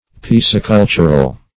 Piscicultural \Pis`ci*cul"tur*al\, a. Relating to pisciculture.